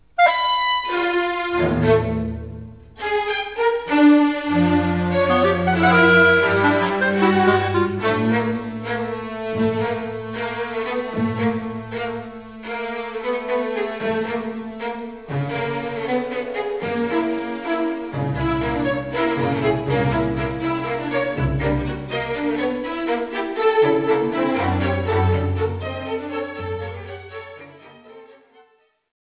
vonószenekarra = for strings
for clarinet, strings and harpsichord
kürtre és kamarazenekarra = for horn and chamber orchestra